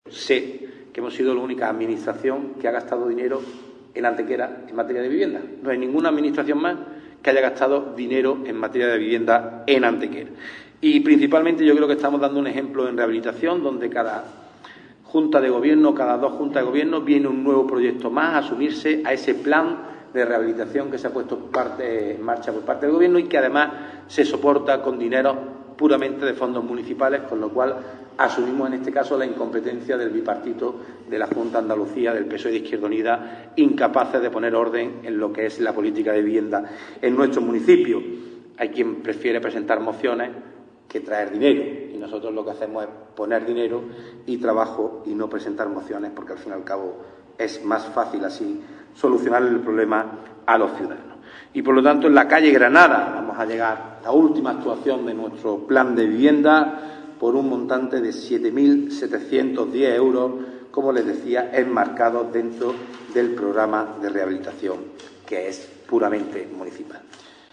El teniente de alcalde-portavoz del Equipo de Gobierno, Ángel González, ha comparecido en la mañana de hoy ante los medios de comunicación para informar sobre los principales acuerdos adoptados en la sesión ordinaria de hoy de la Junta de Gobierno Local, siguiendo así el compromiso de información y transparencia de la gestión municipal.
Cortes de voz